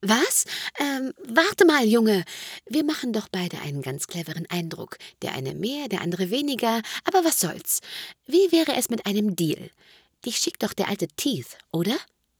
Everlight - Elfen an die Macht Sample 1 Datei herunterladen weitere Infos zum Spiel in unserer Spieleliste Beschreibung: Dieses Sample gibt ein Stück des Dialogs von der ersten Begegnung zwischen Melvin und Fenny, unmittelbar nachdem Melvin in Tallen angekommen ist wieder.